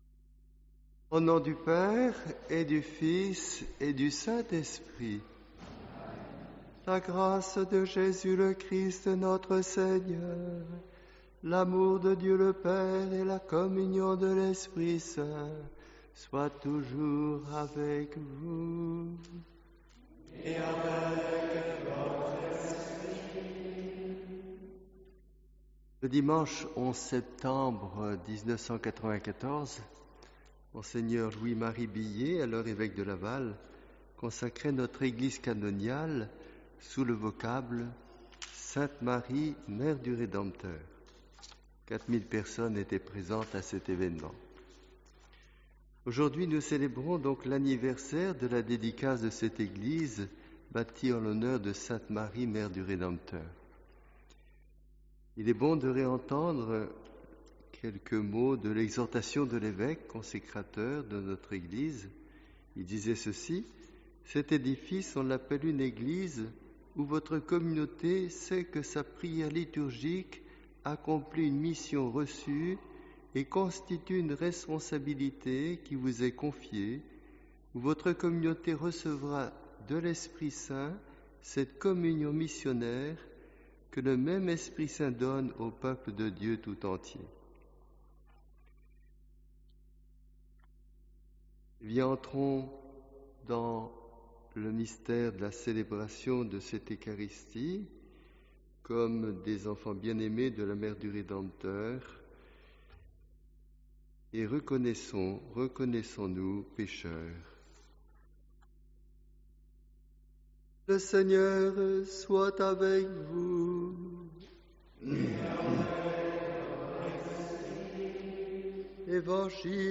Texte des homélies à venir Intégralité de la Messe sur Youtube Textes des lectures voir année C : Is 56/1,6-7; […]